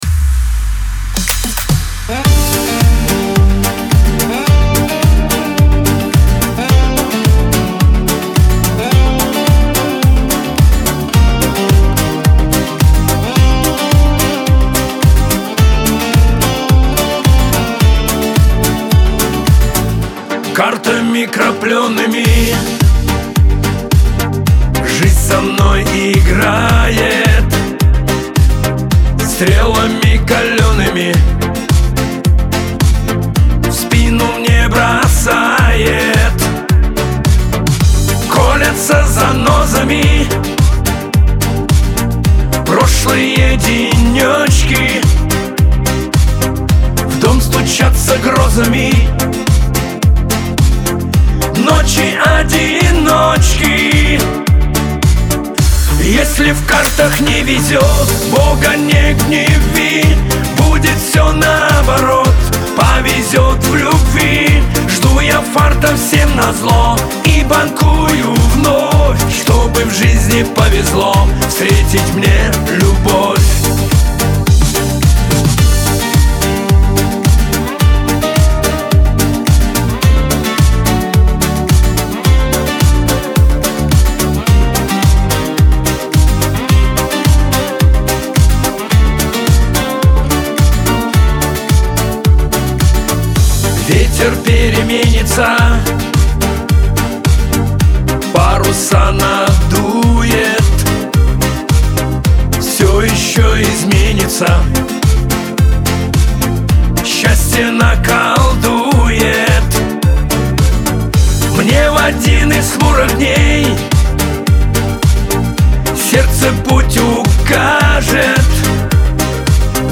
Шансон , эстрада
грусть